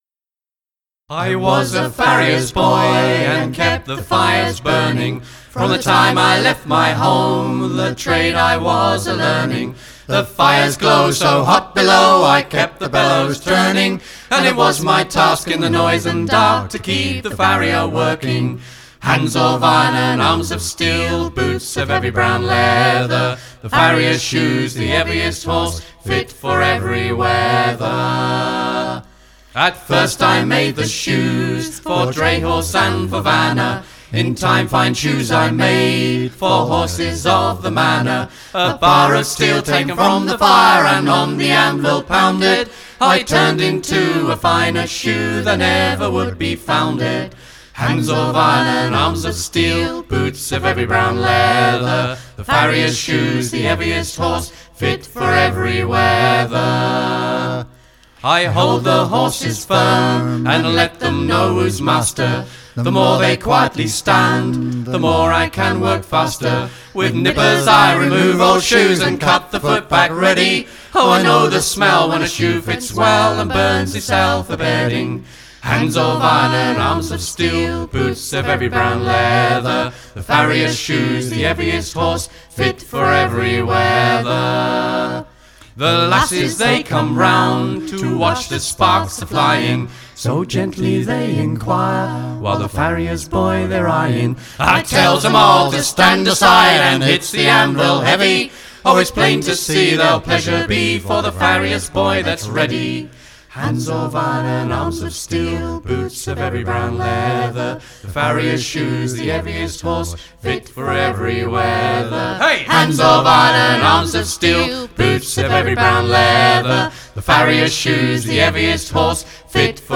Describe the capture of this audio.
Recorded at East Surrey College